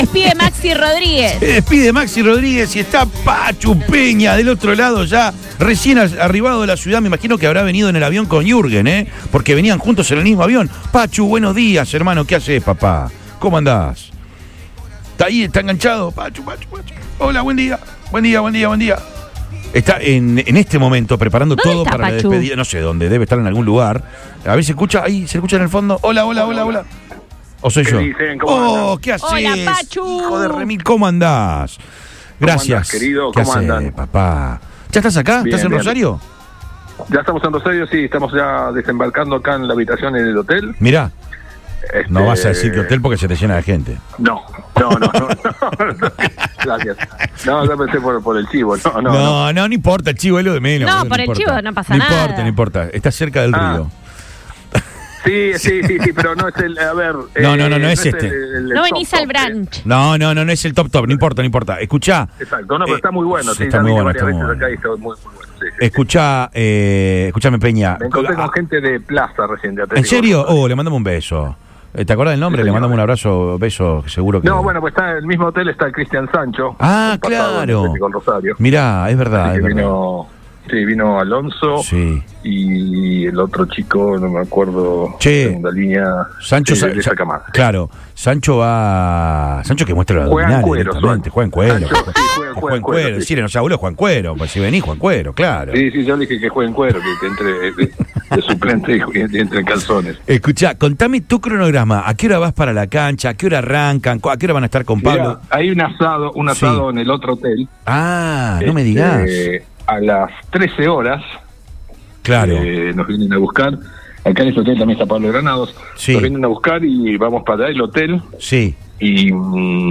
EN RADIO BOING
A pocas horas de que comience el partido despedida de Maxi Rodríguez, el humorista Pachu Peña pasó por los micrófonos de Lo Mejor de Todo, por Radio Boing, donde dio algunos detalles de lo que ocurrirá en el Parque.